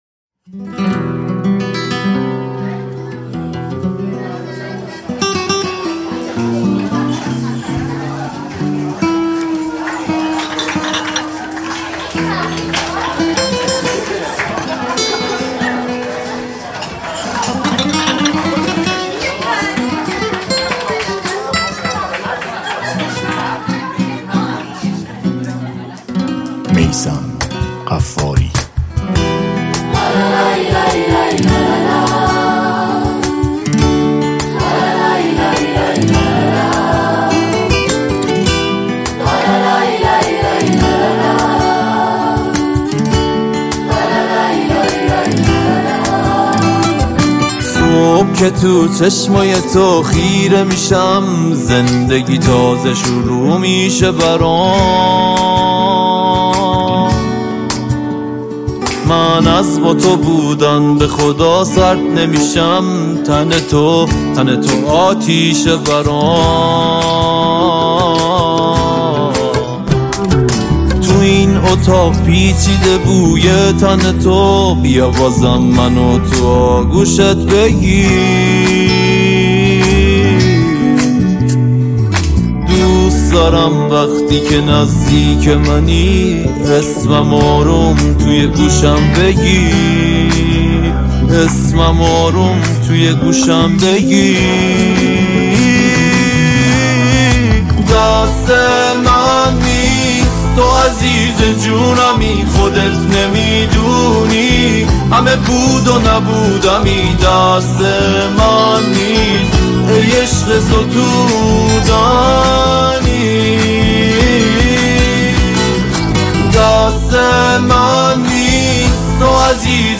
تک آهنگ ها ، غمگین